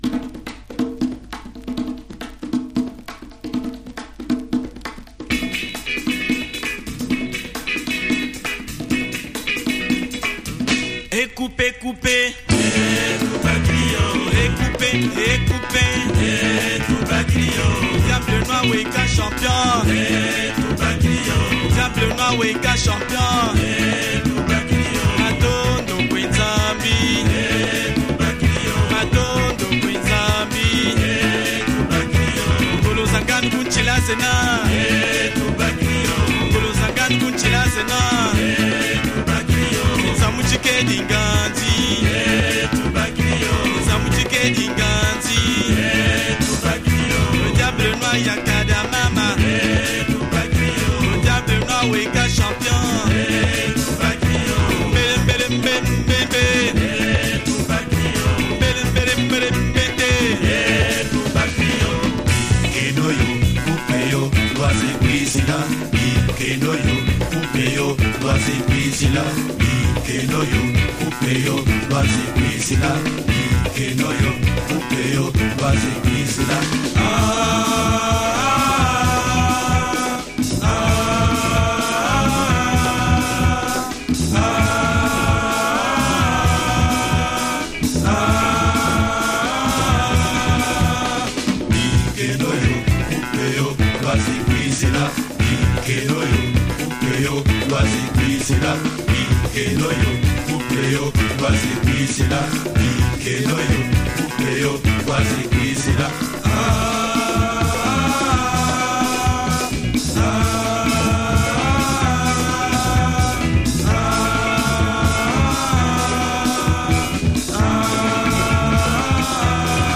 Recorded in Paris (France) in the mid 70s
Instant dance floor burner, hope you’ll like it – enjoy !